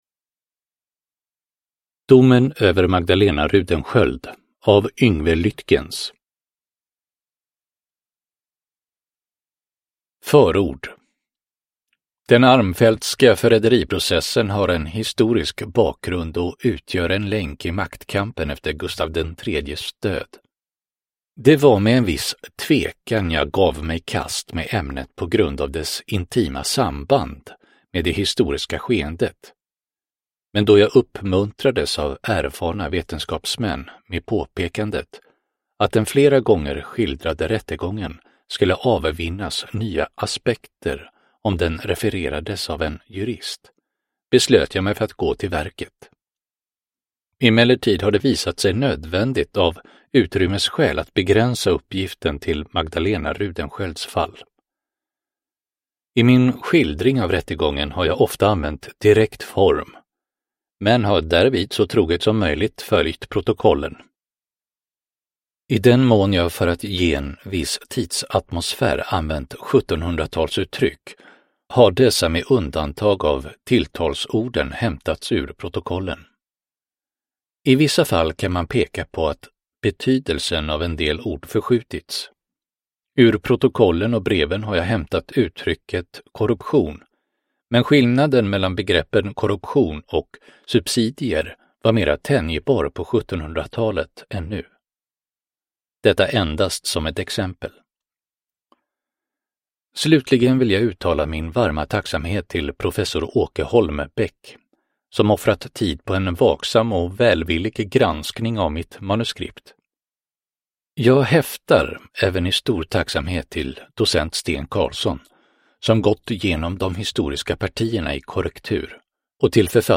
Domen över Magdalena Rudensköld : Historiska mord del 3 – Ljudbok – Laddas ner